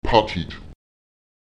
Lautsprecher patet [Èpatet] die Hand (das Greiforgan)